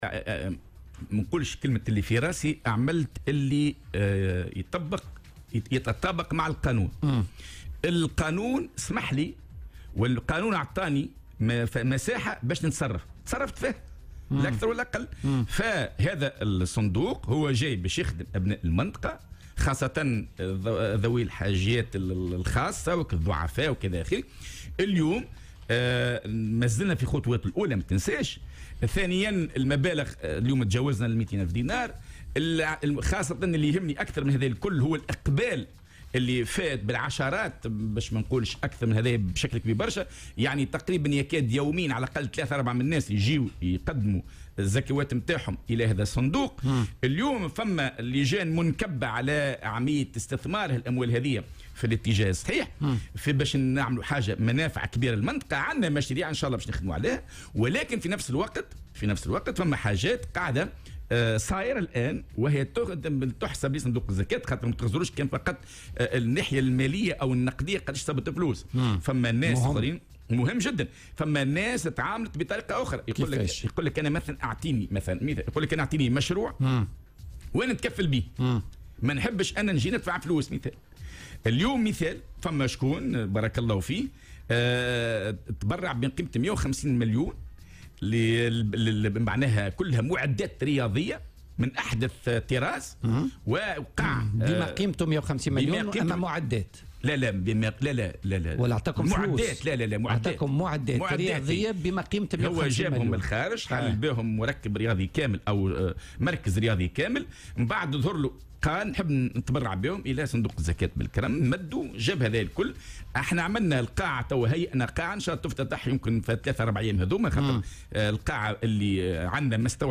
وأضاف في مداخلة له اليوم على "الجوهرة أف أم" أن أحد المواطنين قد تبرّع بمعدات وآلات رياضية بلغت قيمتها 150 ألف دينار، موضحا أنه سيتم استغلالها في تجهيز مركز رياضي سيفتح أبوابه قريبا، إضافة إلى قاعة رياضية نسائية.